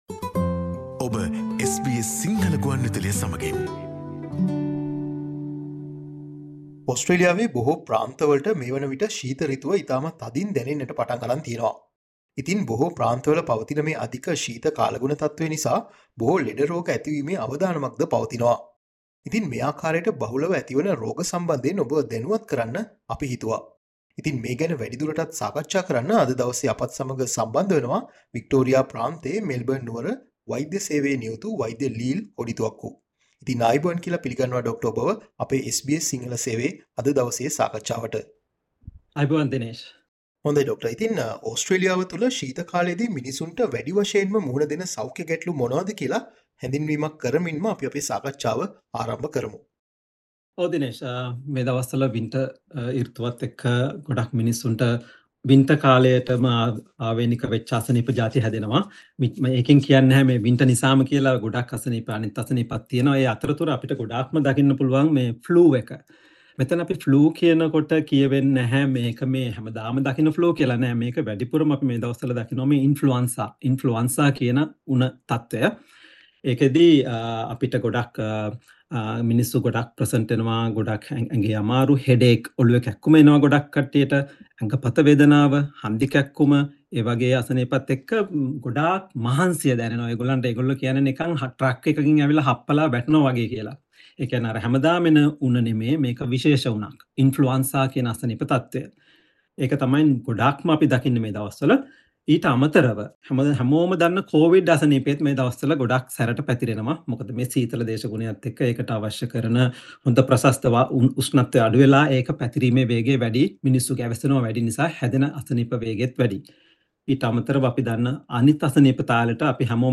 SBS Sinhala discussion on reducing health risks during winter season in Australia.